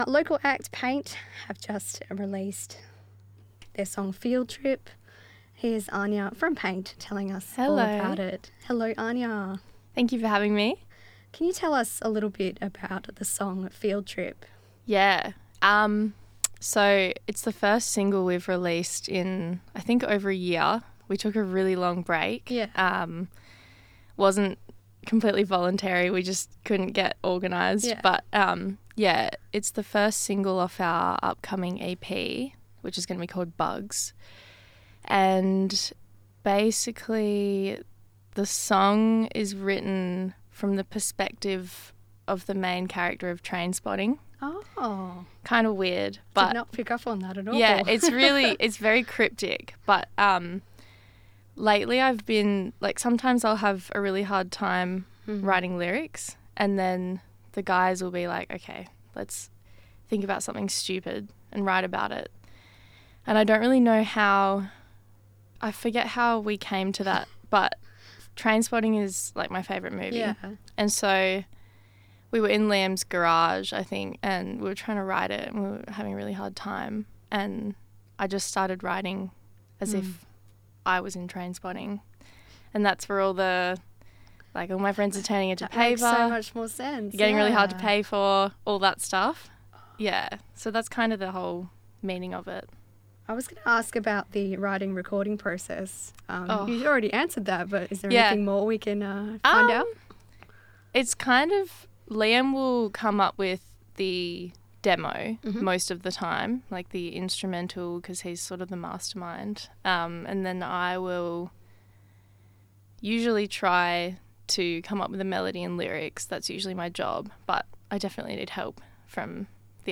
Paint drop by the RTRFM studios to chat new single Field Trip - RTRFM